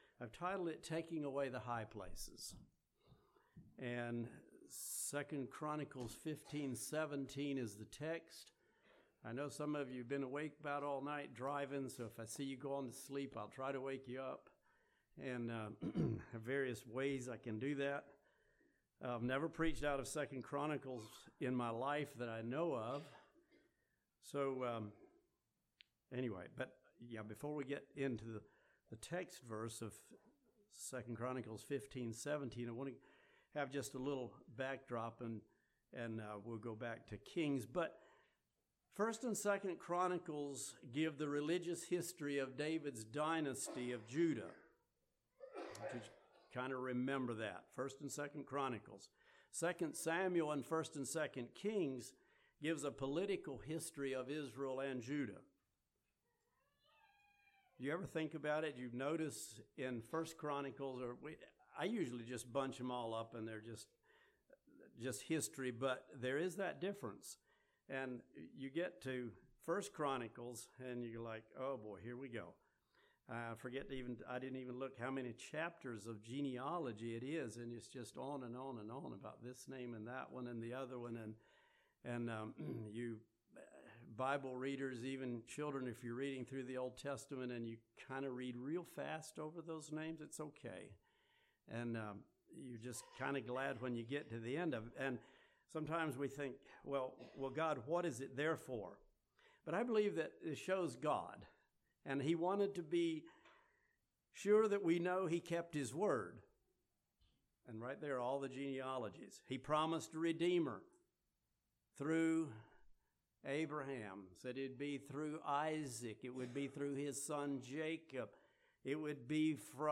This sermon identifies high places that we need to guard against and take away in our day.